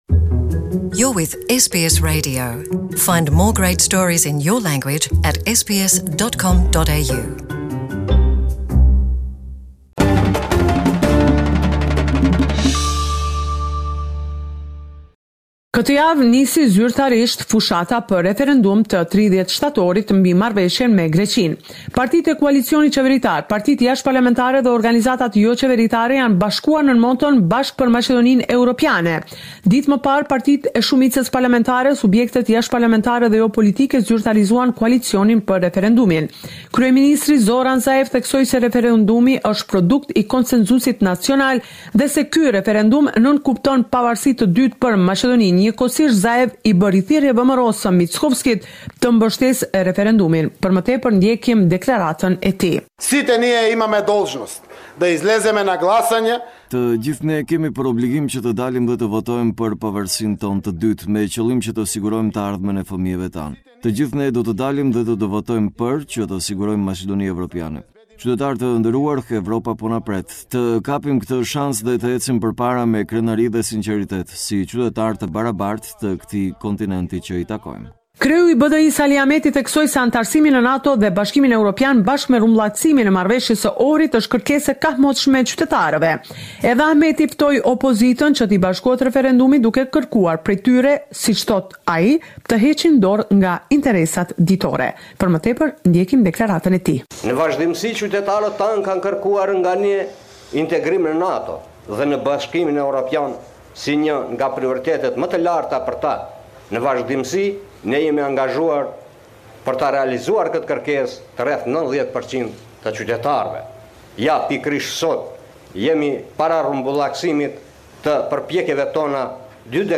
This is a report summarising the latest developments in news and current affairs in Macedonia